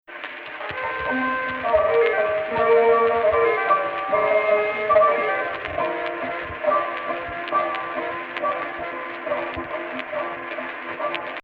Not to speak of the obvious vocal power.
Actual Key: Rast on F